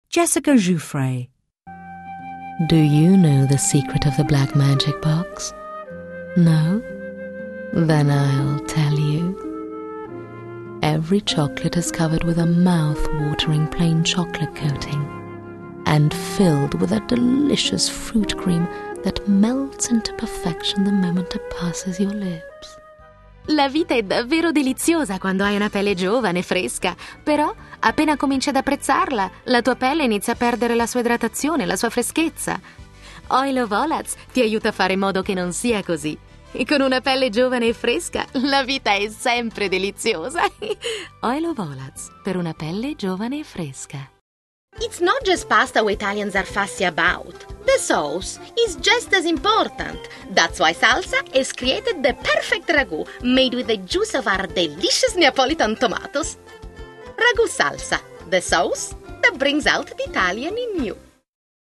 Italian voiceover artist: contact her agent direct for female Italian voice overs